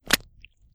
High Quality Footsteps
STEPS Pudle, Walk 27.wav